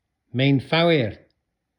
9. мэйнфауир